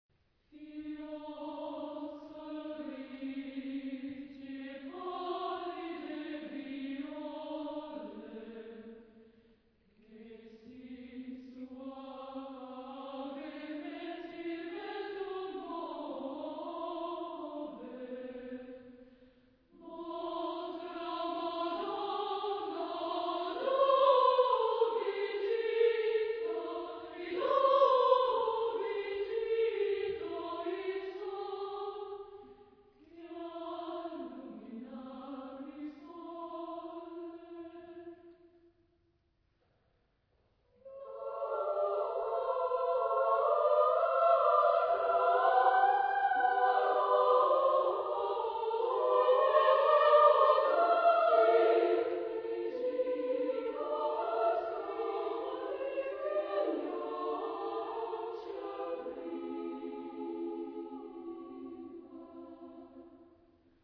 Genre-Style-Forme : Profane ; contemporain ; Madrigal
Tonalité : ré mode de la